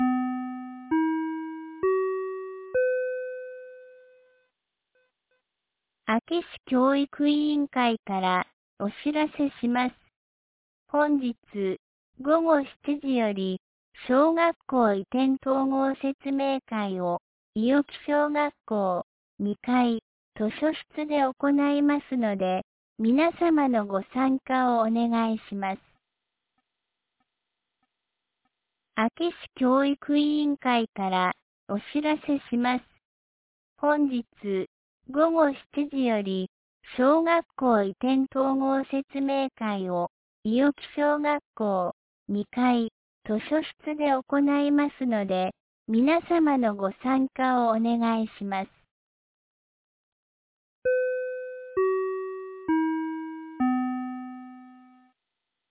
2026年01月20日 12時50分に、安芸市より伊尾木へ放送がありました。